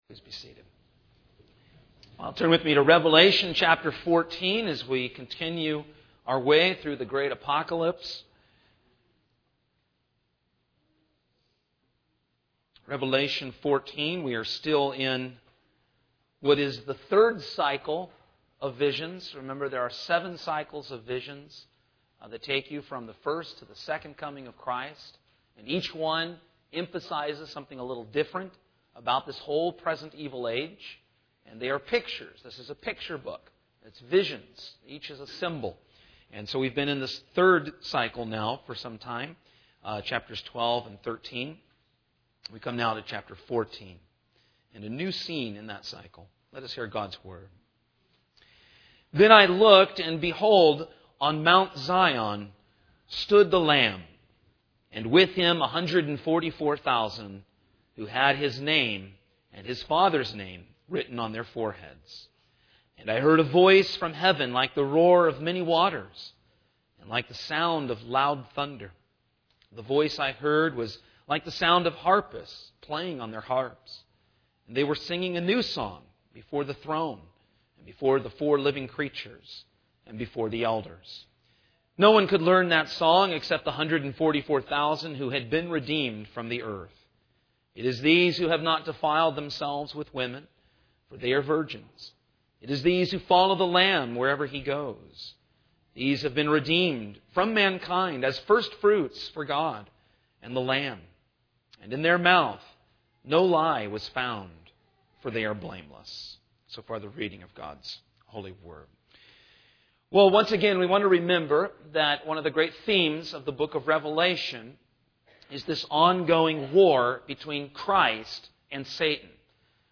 Revelation — Sermons — Christ United Reformed Church